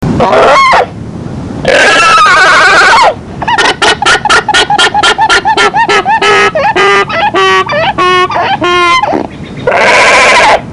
Alpaca Scream and Alarm Call
A loud, high-pitched sound indicating: ✔ Extreme fear or pain ✔ Aggressive encounters ✔ Need for immediate attention
Alpaca-Scream-and-Alarm-Call.mp3